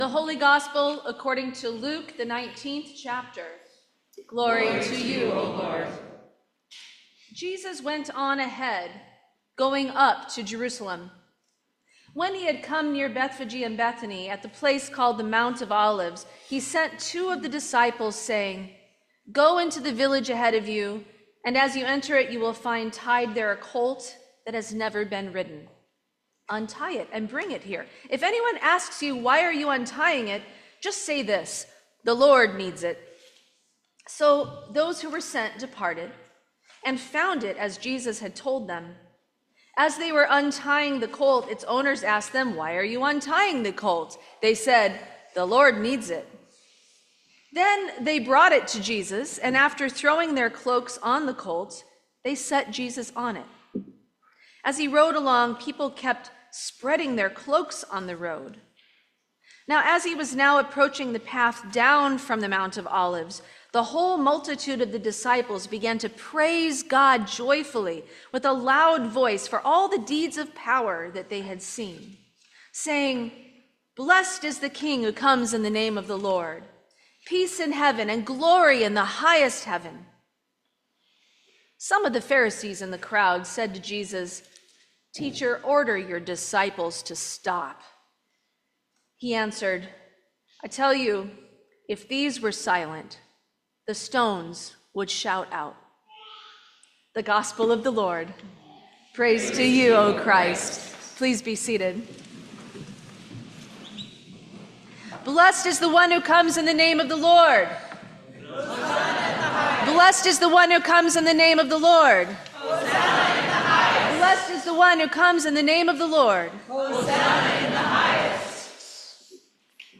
Sermon for Palm Sunday 2025